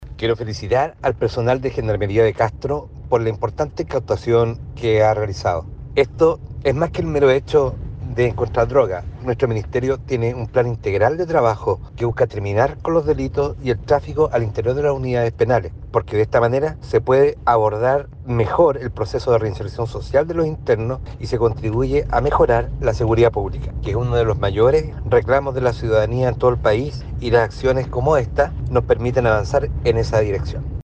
Por su parte, el Seremi de Justicia y Derechos Humanos de la región de Los Lagos, Enrique Cárdenas, felicitó al personal de Gendarmería del CDP de Castro por la incautación de droga realizada al interior del recinto penal: